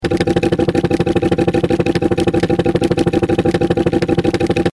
Звук мотора мультяшного автомобиля